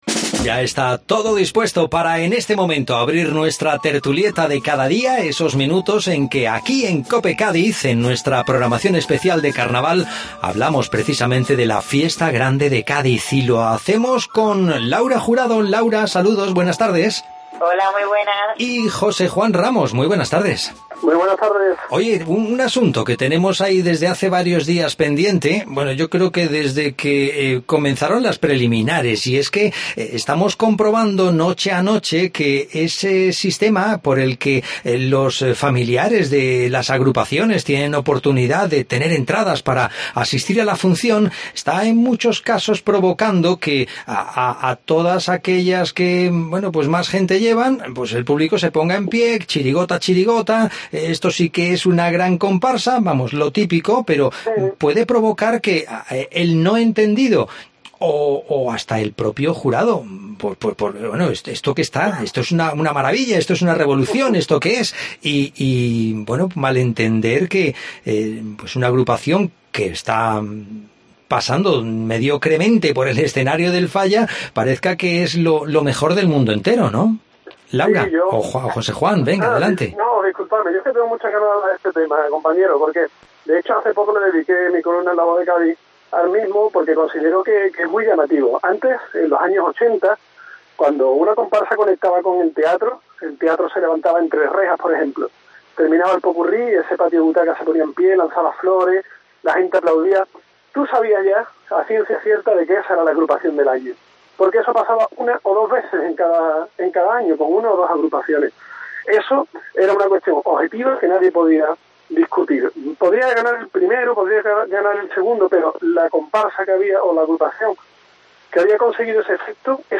AUDIO: Hablamos del COAC 2018 y todo lo que sucede en el concurso. Además de escuchar las mejores coplas de la noche anterior.